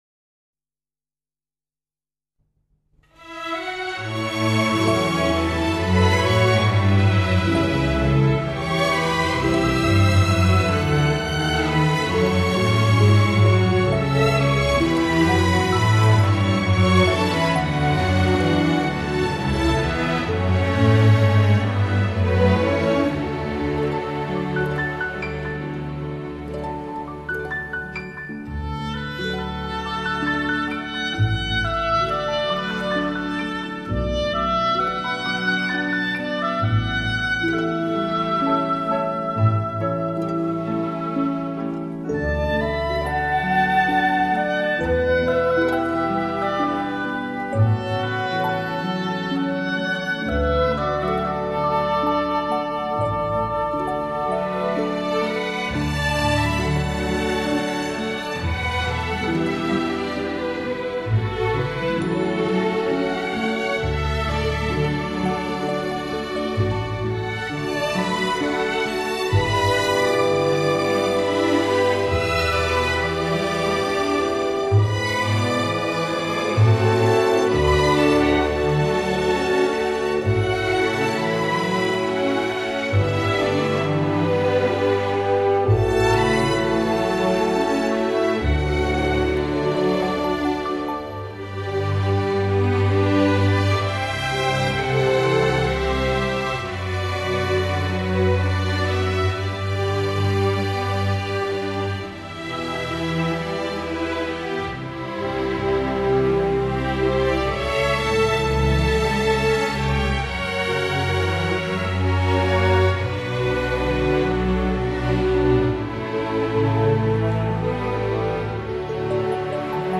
发烧极级24BIT/192KHZ数码处理